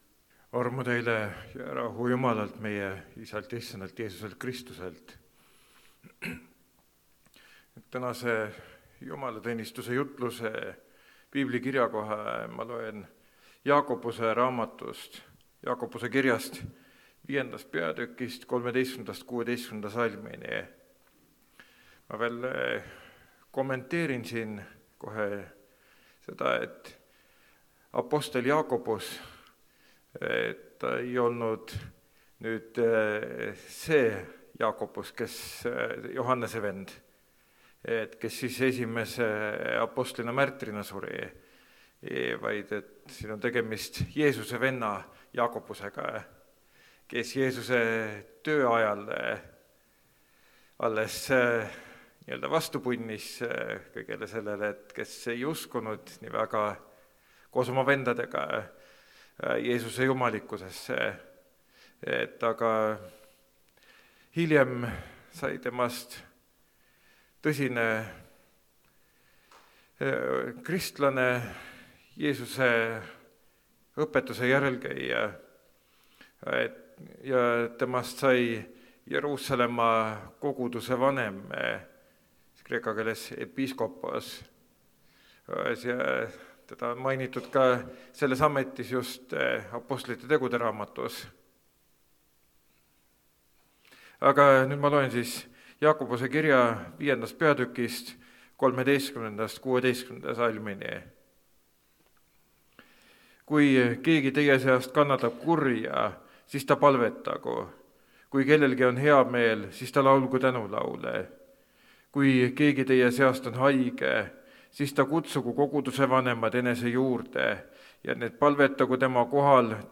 Palve vägi (Rakveres)
Jutlused